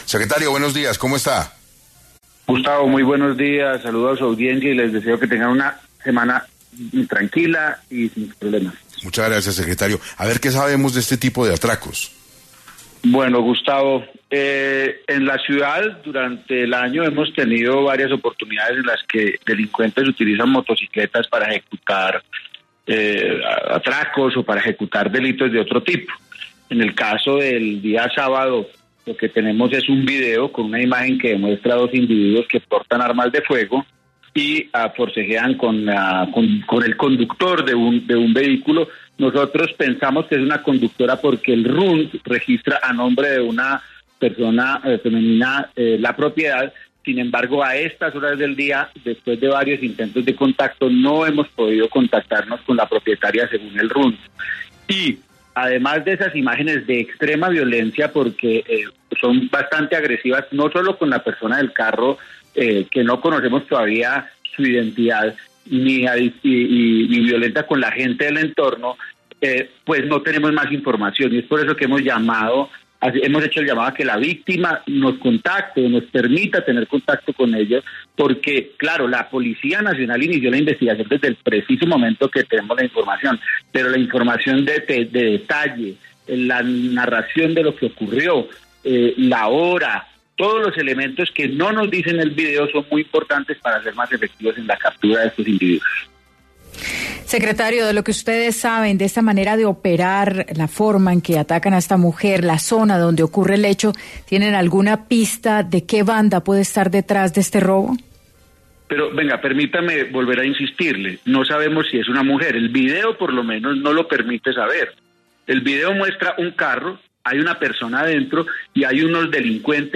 En 6AM de Caracol Radio estuvo César Restrepo, Secretario de Seguridad de Bogotá, quien habló sobre dos situaciones: las hipótesis sobre el asesinato del hijo del inspector y de la policía e información que tienen del paradero de los delincuentes que atracaron a la mujer que conducía un vehículo de alta gama en Chapinero.